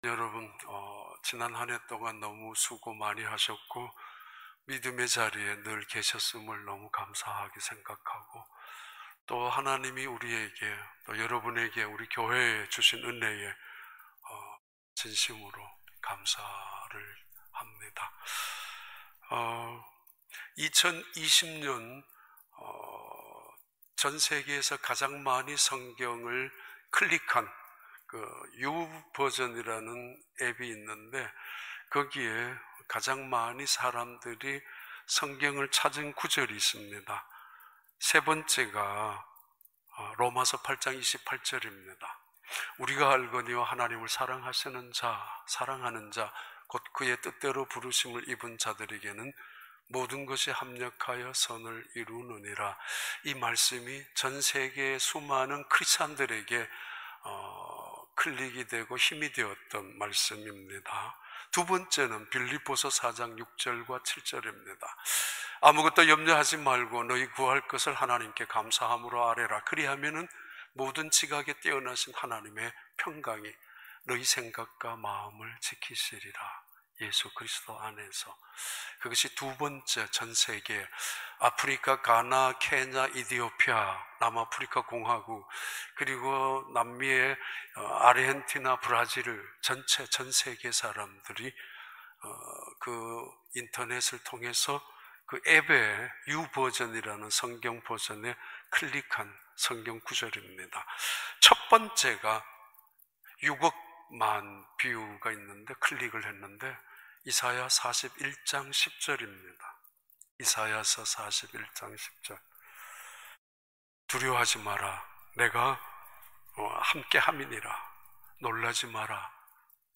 2021년 1월 3일 주일 4부 예배